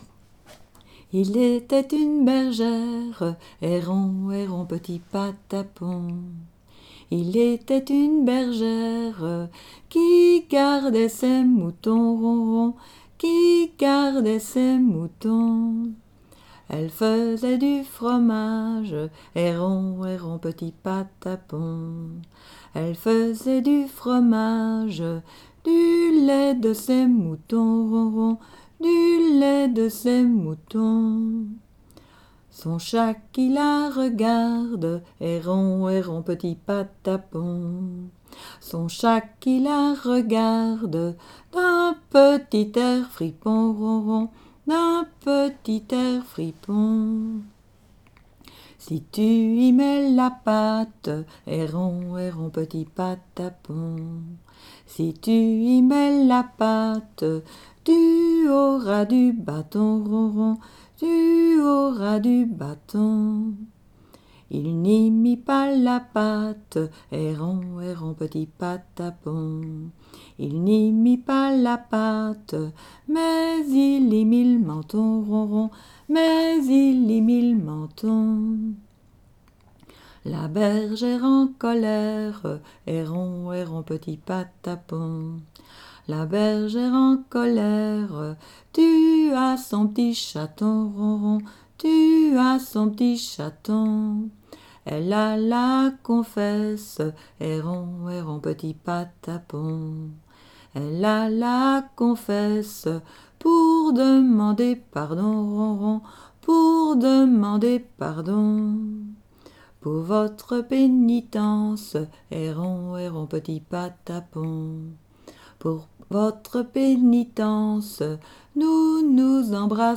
Genre : chant
Type : chanson d'enfants
Aire culturelle d'origine : Haute Ardenne
Lieu d'enregistrement : Malmedy